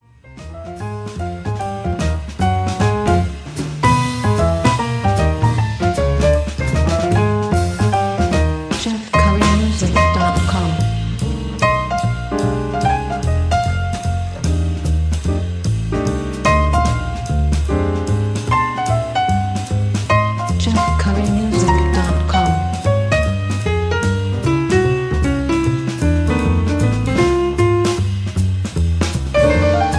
Swinging jazz/blues track with a strong melody.